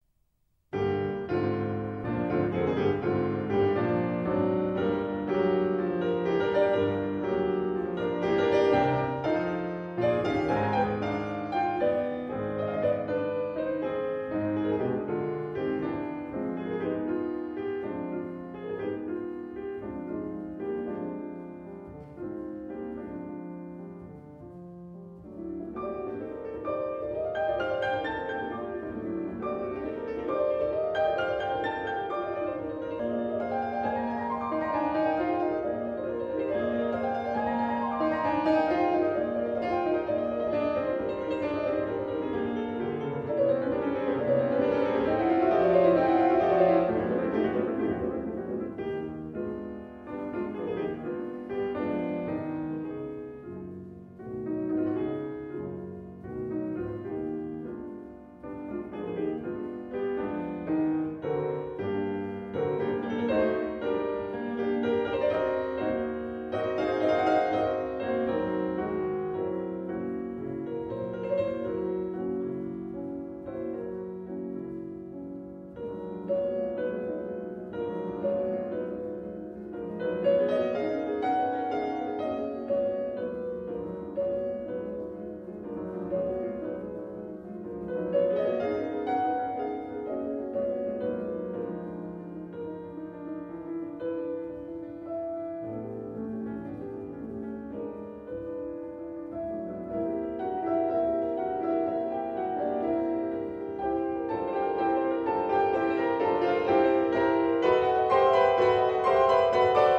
String Quartet in G minor
Animé et très décidé